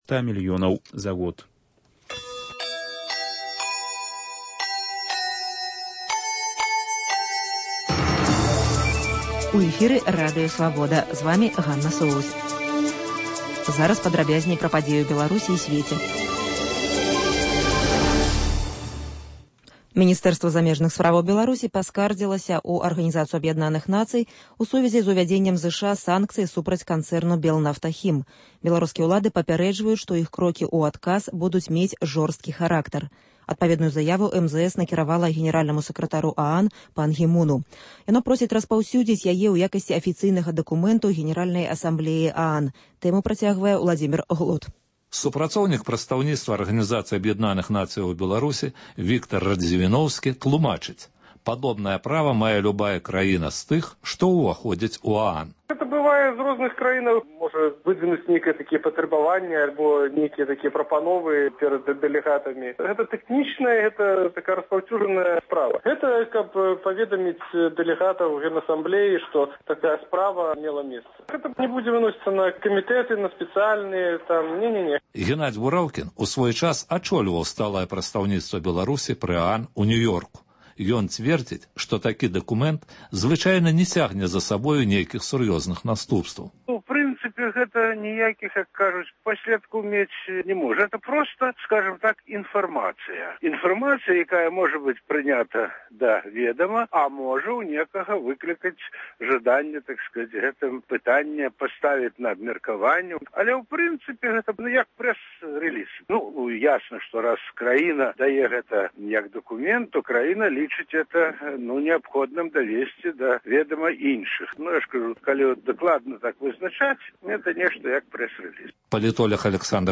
Паведамленьні нашых карэспандэнтаў, галасы слухачоў, апытаньні на вуліцах беларускіх гарадоў і мястэчак.